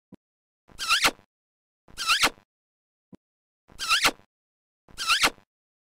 دانلود صدای بوس 1 از ساعد نیوز با لینک مستقیم و کیفیت بالا
جلوه های صوتی